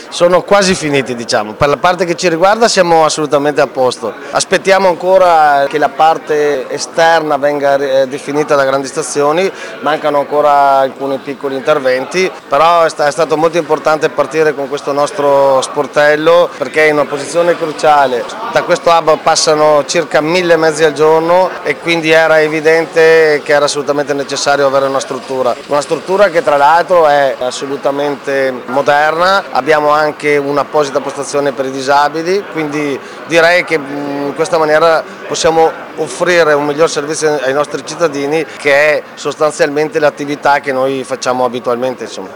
All’inaugurazione erano presenti: